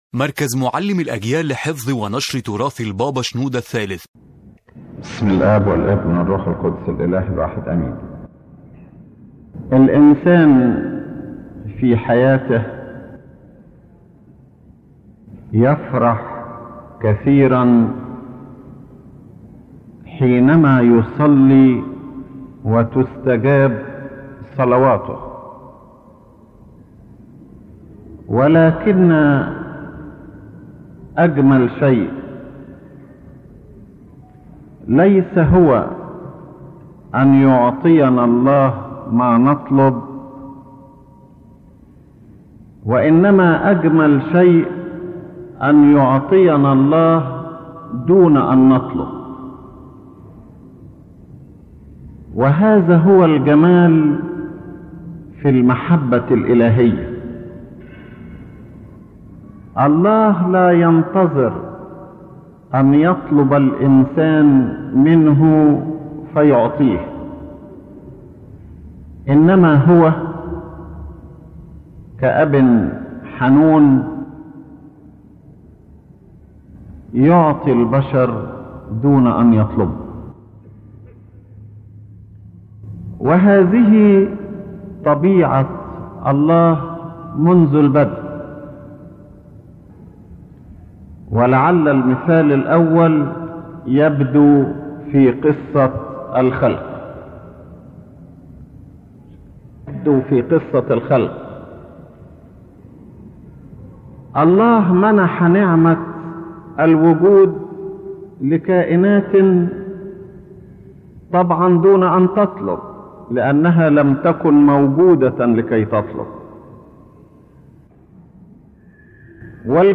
The lecture explains that God’s love and gifts do not depend only on human requests, but that God in His generosity and goodness gives man many blessings without him asking or even thinking about them.